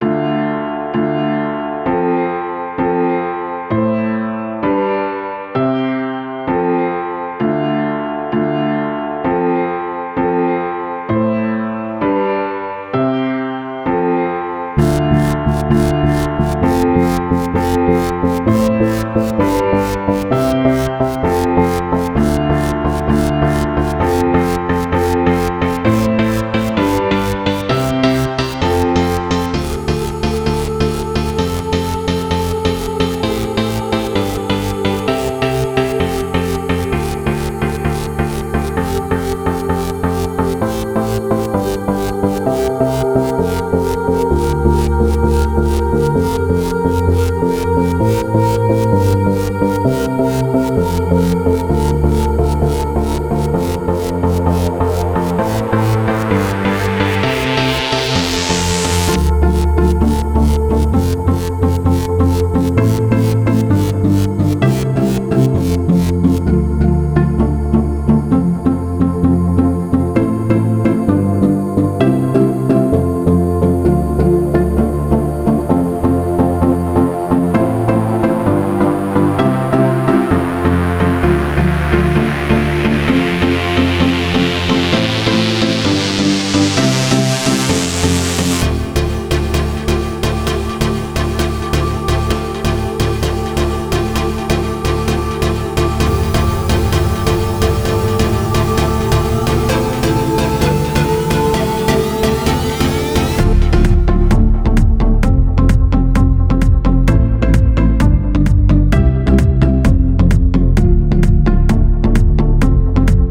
It's a club track so I'm thinking of making a radio edit as well as the original edit. It currently has no vocals but I am thinking of recording some, I can't sing but I'm thinking of adding some effects and just having more background vocals like a lot of club tracks have.
I played around with the velocity and got the sound I wanted, I then used serum to create some plucks and a lead and added reverb to them. I used a piano but put a lot of reverb on it, and reduced the dry, and enhanced the wet to give it a nice dreamy sound. I also added a melody and played around with the velocity to make it sound more humanised. I played around with automation of the cut-off on serum, and also added a filter to the mixer channel (put them all on the same channel) and automated the cut-off I’ve found this gives it a rising effect where it starts tame and in the background, then gradually gets more intense, gives a classic club sound.